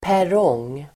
Ladda ner uttalet
perrong substantiv, platform Uttal: [pär'ång:] Böjningar: perrongen, perronger Synonymer: plattform, tågplattform Definition: plattform (vid järnvägsstation) Sammansättningar: tågperrong, mittperrong